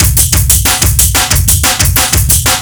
Index of /breakcore is not a good way to get laid/155BPM/silentkillerbreaks